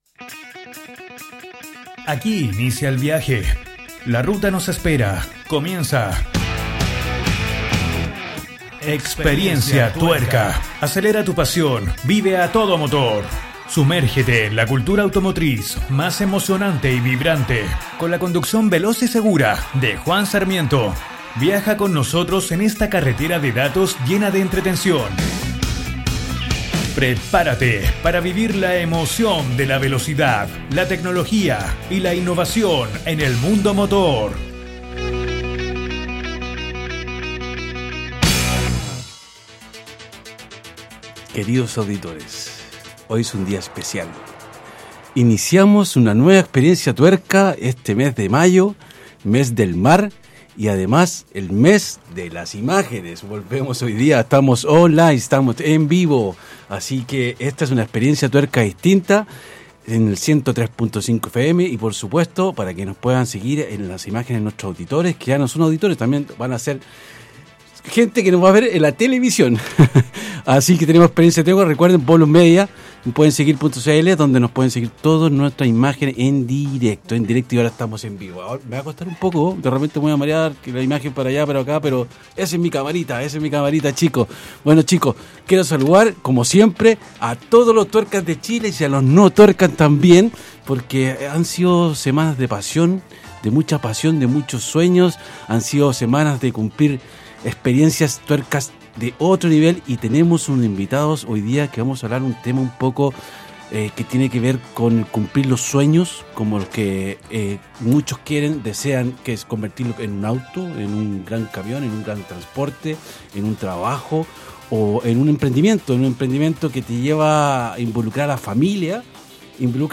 Con un tono amigable, accesible y seguro
quien nos guiará en compañía de expertos invitados